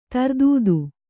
♪ tardūdu